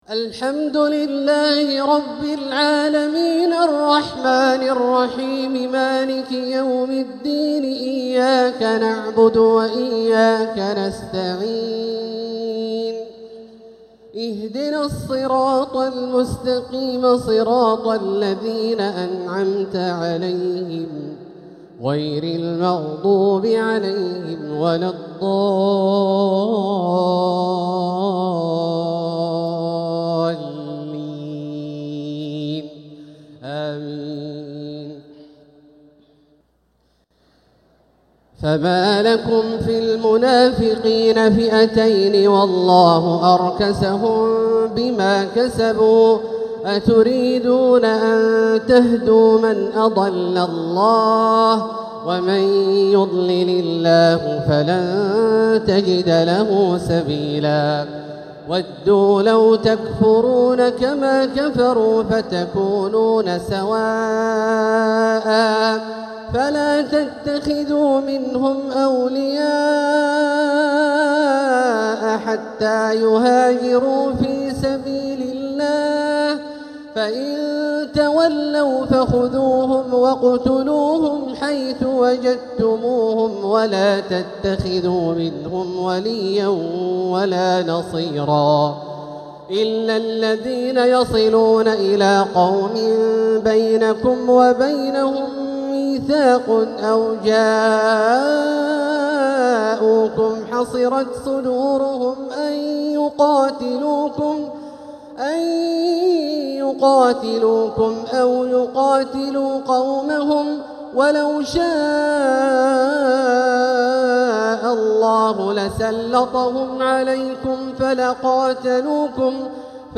تراويح ليلة 7 رمضان 1446هـ من سورة النساء {88-122} Taraweeh 7th night Ramadan 1446H Surah An-Nisaa > تراويح الحرم المكي عام 1446 🕋 > التراويح - تلاوات الحرمين